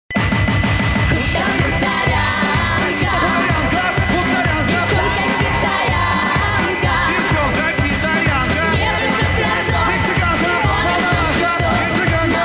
• Пример мелодии содержит искажения (писк).